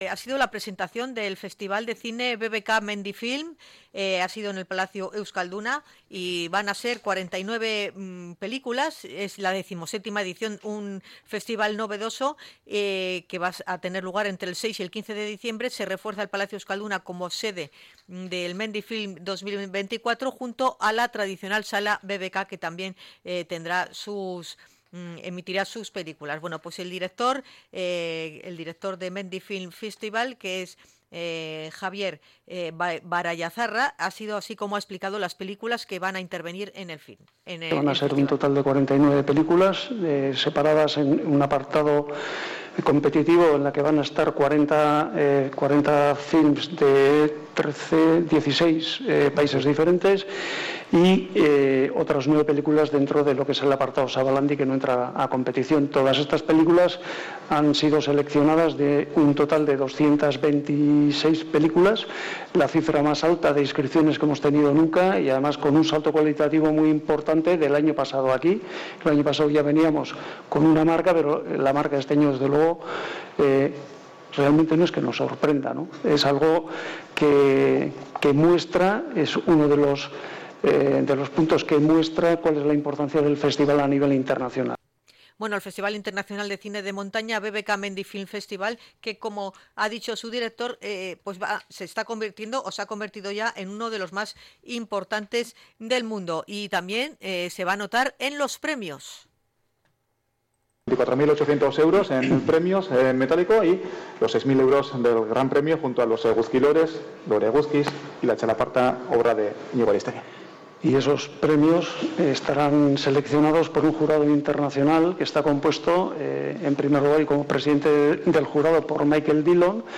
Hemos estado en la presentación de la 17 edición del certamen